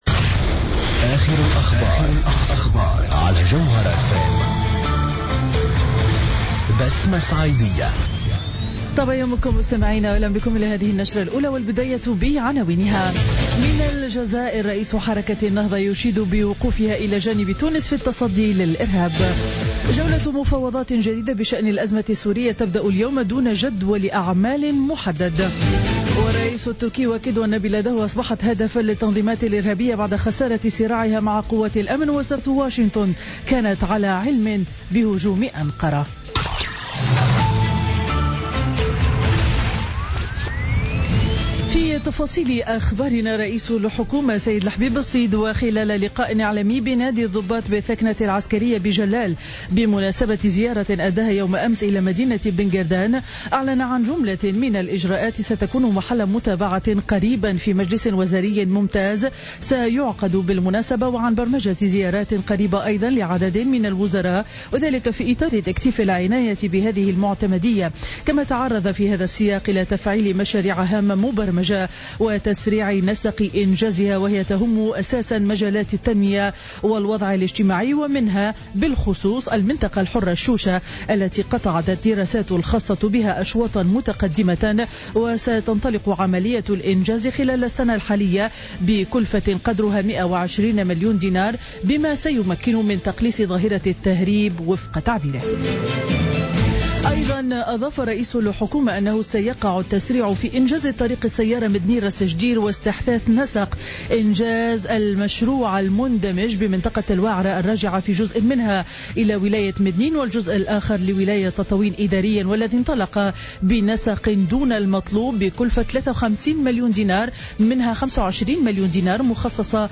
نشرة أخبار السابعة صباحا ليوم الإثنين 14 مارس 2016